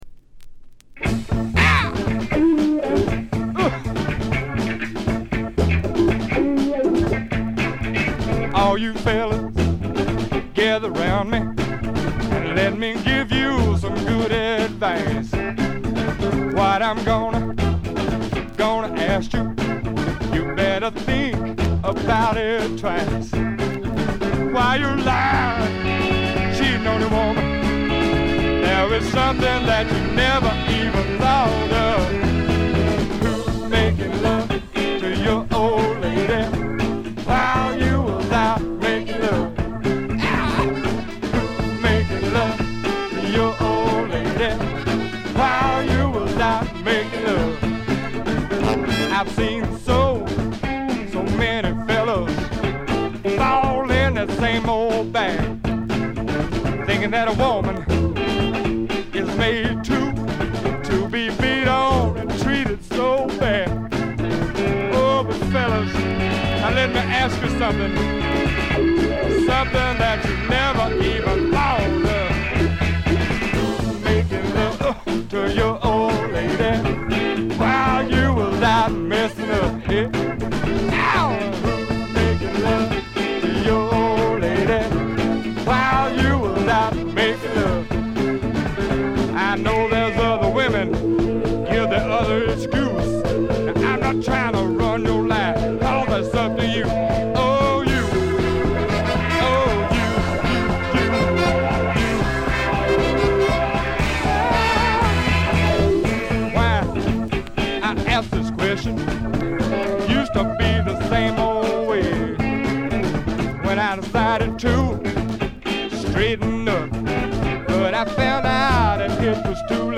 見た目よりよくなくて、バックグラウンドノイズ、チリプチ多め大きめ。
試聴曲は現品からの取り込み音源です。